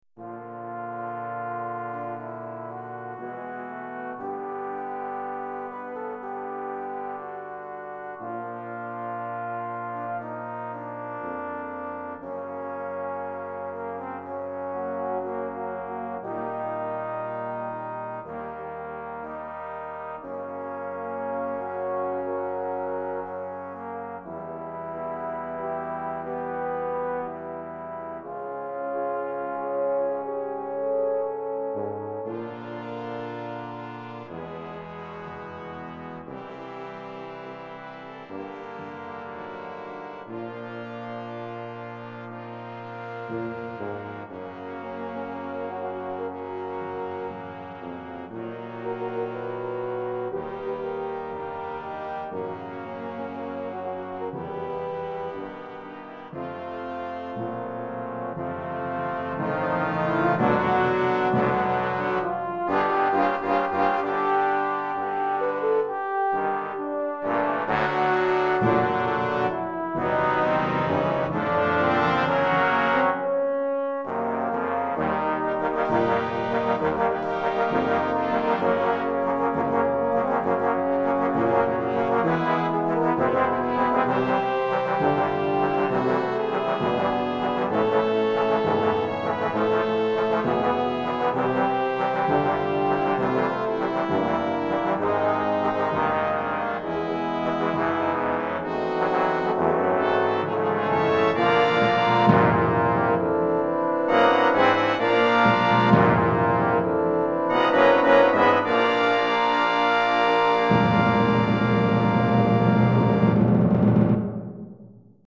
Ensemble de Cuivres et Percussions